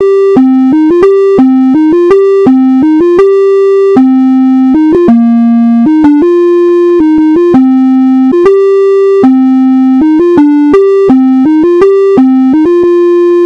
A minimal sound generating coding challange